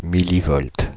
Ääntäminen
France (Île-de-France): IPA: /mi.li.vɔlt/